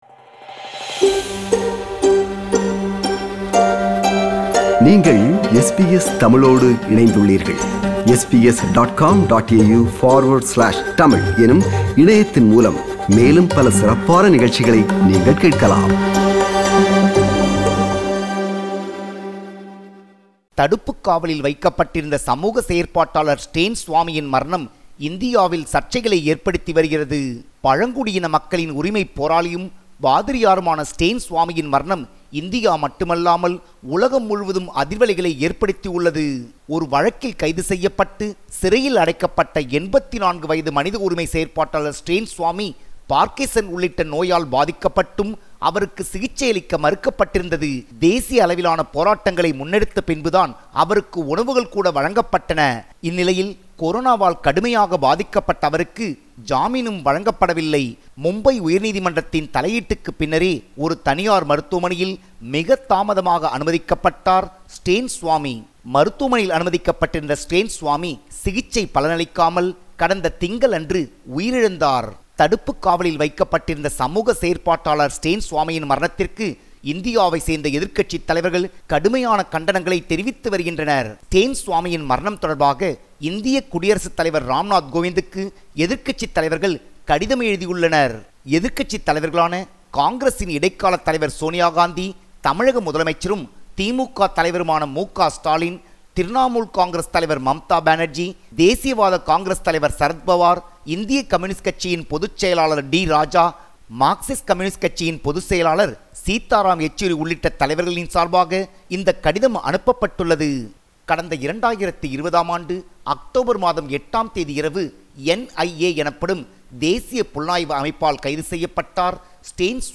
கூடுதல் விவரங்களுடன் இணைகிறார் நமது தமிழக செய்தியாளர்